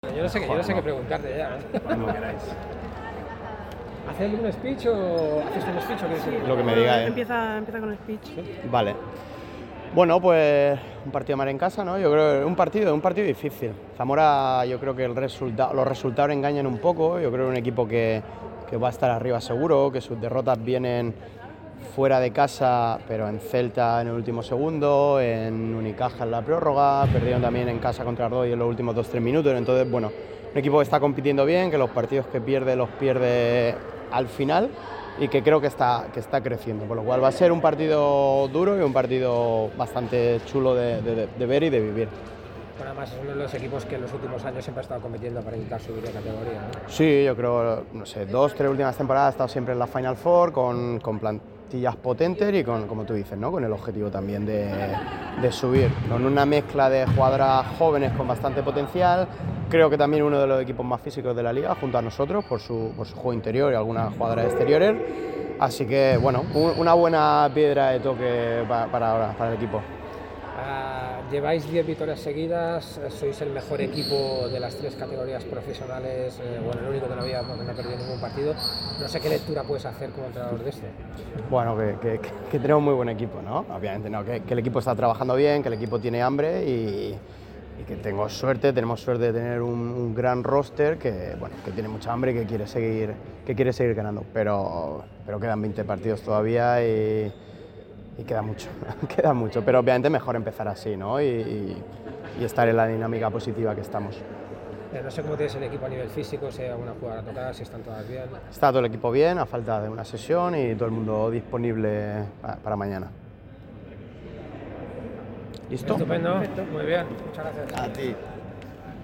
El club y la compañía han formalizado el acuerdo en la rueda de prensa previa al encuentro.